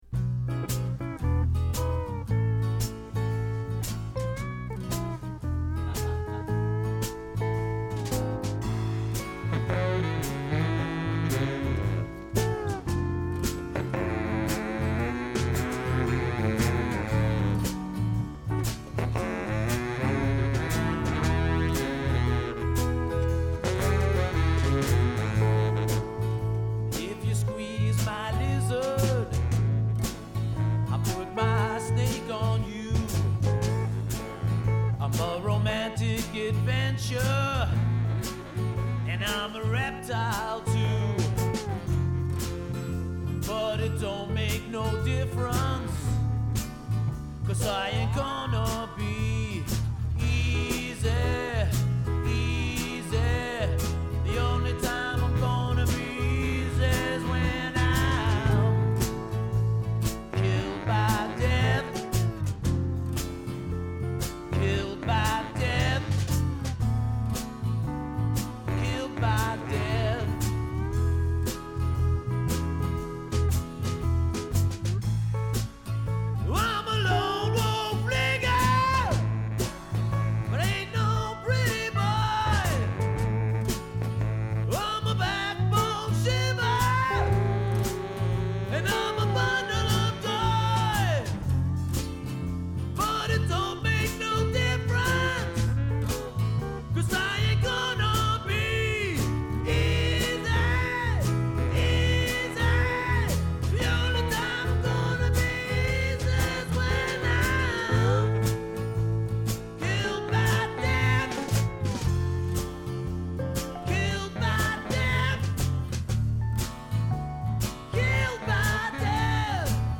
Recorded at the Maid’s Room, NYC
Hawai’ian steel (kika kila)
slack key acoustic guitar (Ki Ho Alu)
baritone saxophone
bass
drums
Stereo (2″ analog)
rough mix